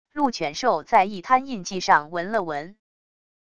鹿犬兽在一滩印记上闻了闻wav音频